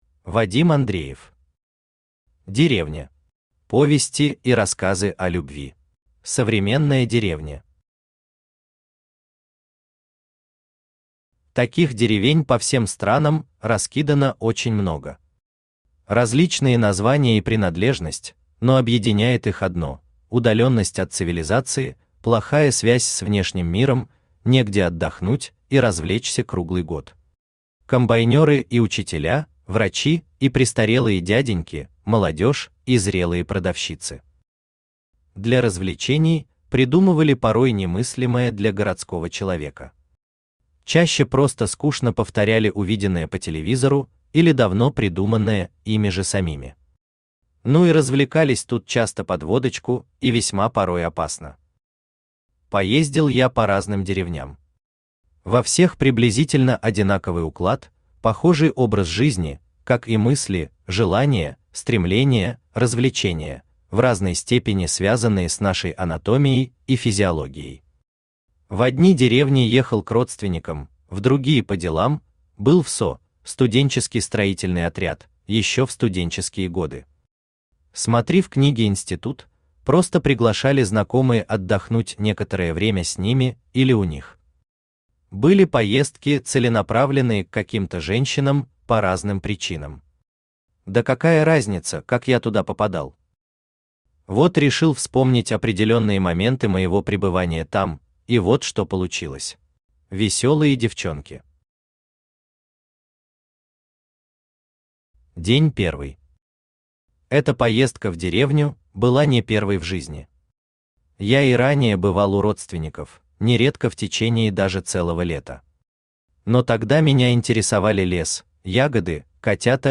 Аудиокнига Деревня. Повести и рассказы о любви | Библиотека аудиокниг
Повести и рассказы о любви Автор Вадим Андреев Читает аудиокнигу Авточтец ЛитРес.